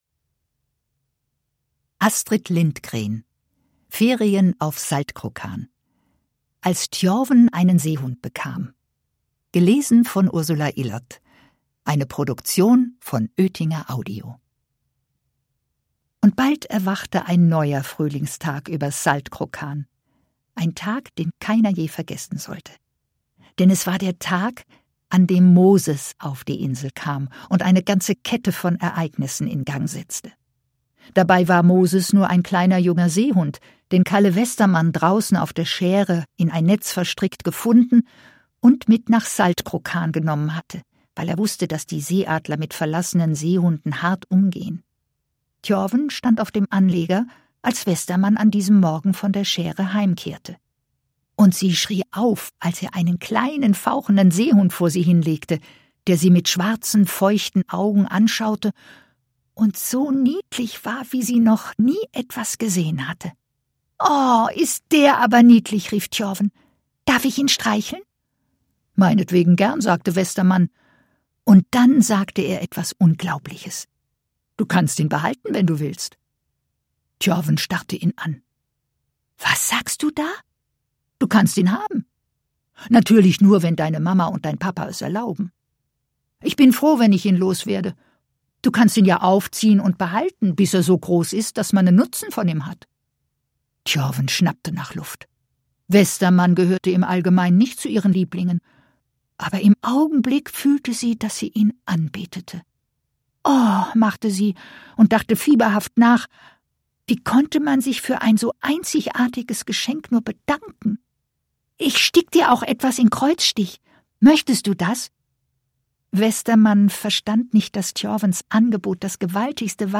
Hörbuch: Ferien auf Saltkrokan.